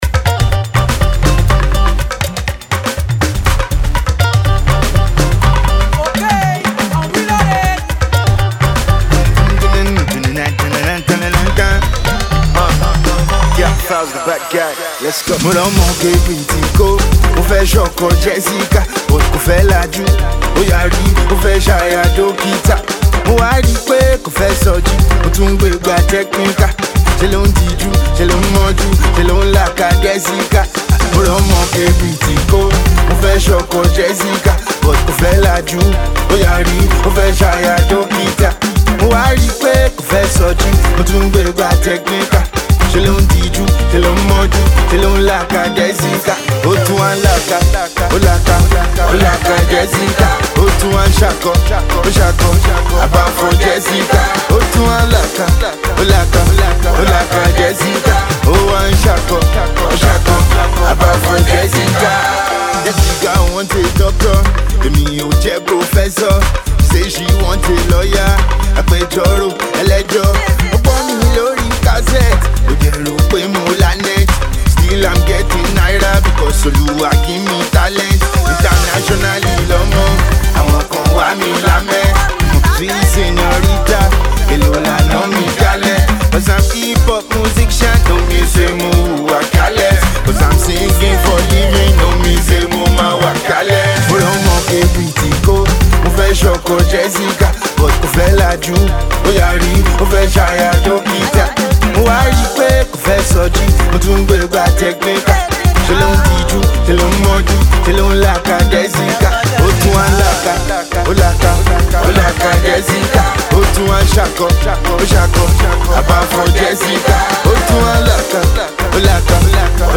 humorous, yet catchy song